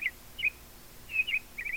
bird noise
bird.wav